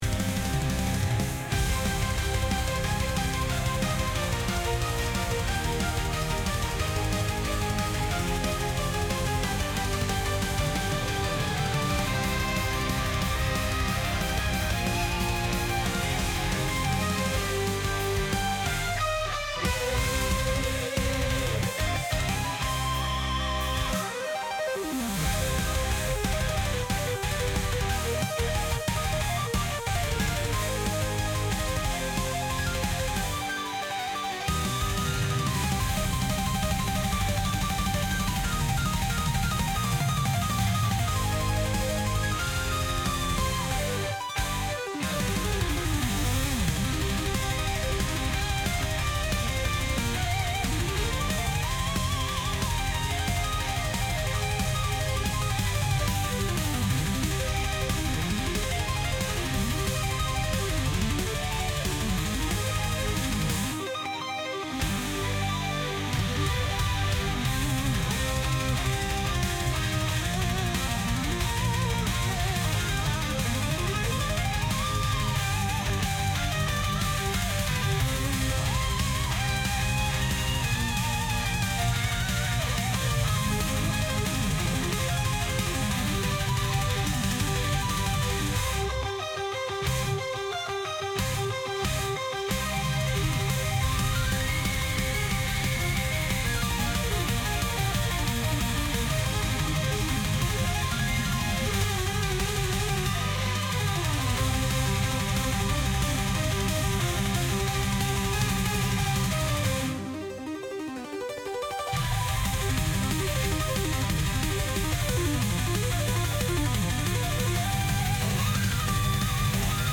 wicked-quest / sounds / ambience / Spider's Domain.ogg